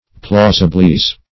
Plausibleize \Plau"si*ble*ize\, v. t. To render plausible.